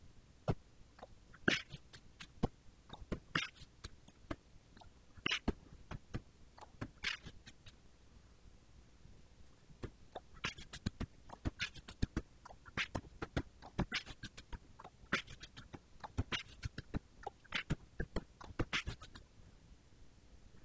Битбокс под rap, как это?
Кстати, второй бит - чуть изменённый кавер на мой недоделанный минус, подойти должен отлично, там тоже скорость под восемдесят ударов.